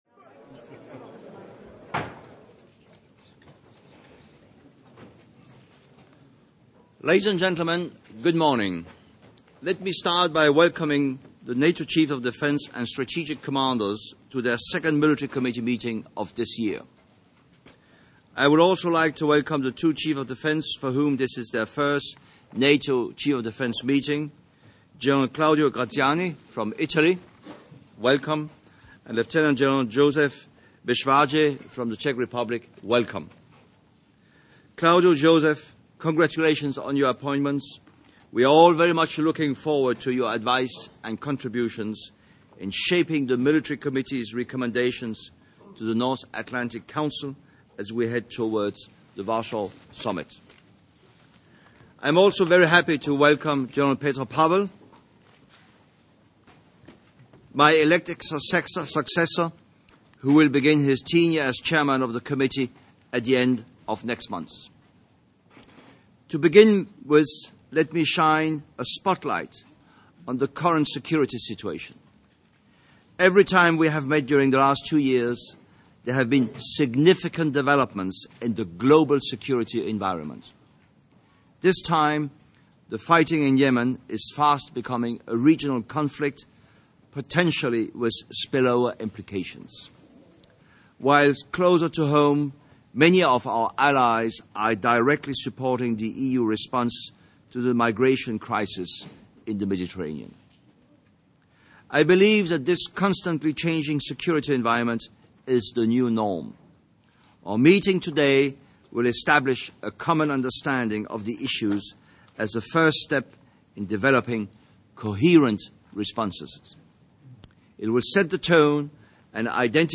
Opening statements by Chairman of the Military Committee, General Knud Bartels, Supreme Allied Commander, Europe - General Philip M. Breedlove and Supreme Allied Commander, Transformation - General Jean-Paul Paloméros, followed by Q&A session Photos Transcript (incl.